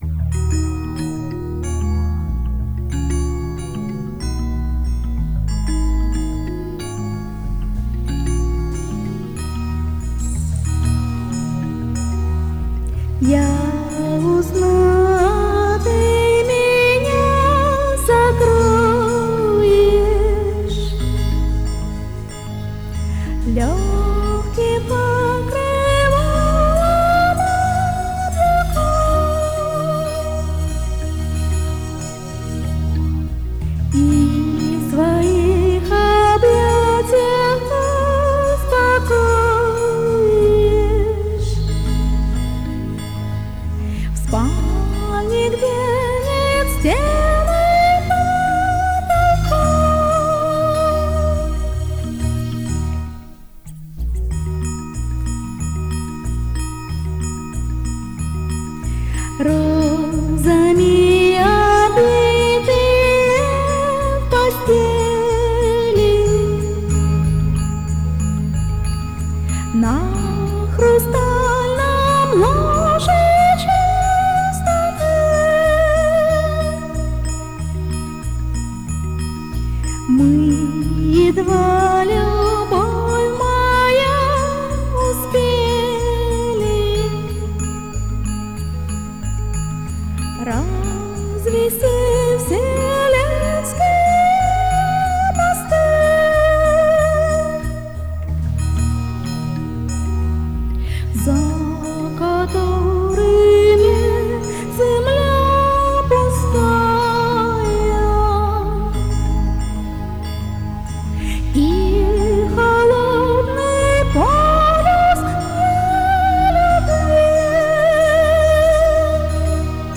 Духовная музыка Медитативная музыка Мистическая музыка